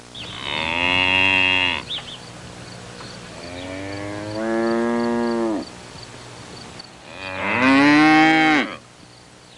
Cow And A Bird Sound Effect
Download a high-quality cow and a bird sound effect.
cow-and-a-bird.mp3